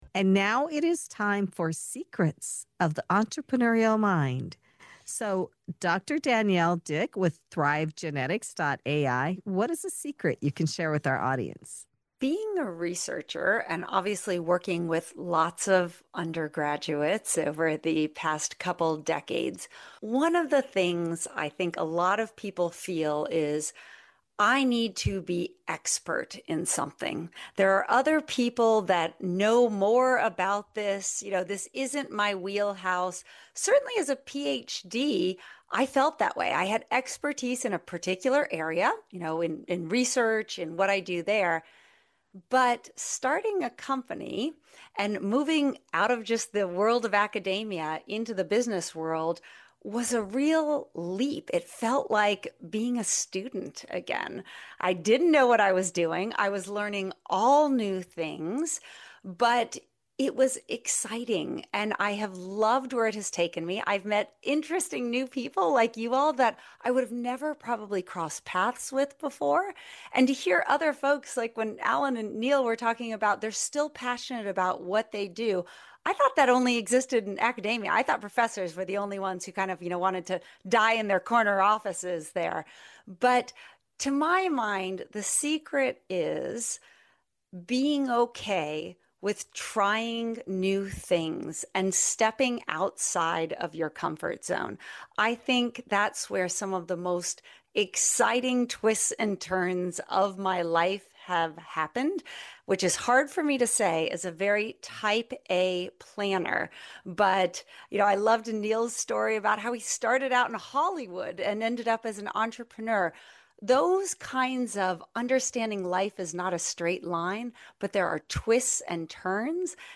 In this segment of "Secrets of the Entrepreneurial Mind" on Passage to Profit Show, a powerhouse panel of founders, researchers, and advisors share the hard-earned lessons they wish they’d known sooner.